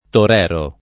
vai all'elenco alfabetico delle voci ingrandisci il carattere 100% rimpicciolisci il carattere stampa invia tramite posta elettronica codividi su Facebook torero [ tor $ ro ] s. m. — sp. torero [ tor % ro ]; pl. toreros [ tor % ro S ] — cfr. toreador